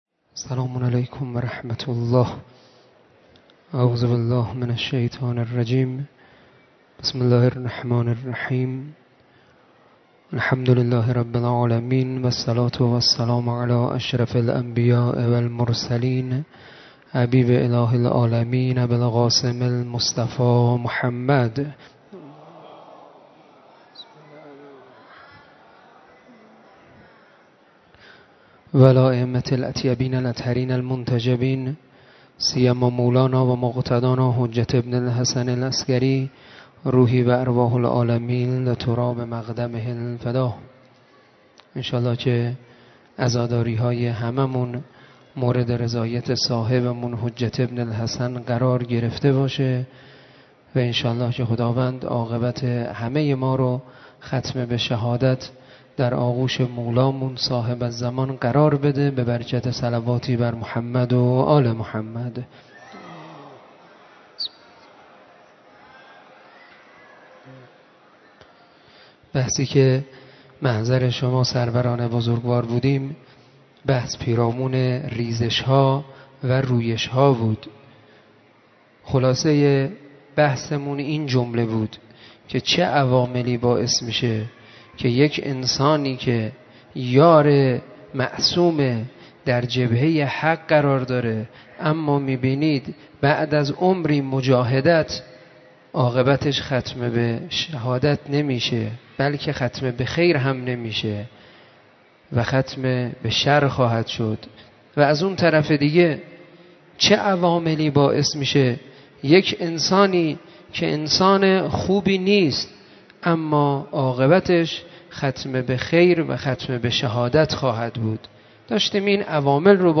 سخنرانی مکتوب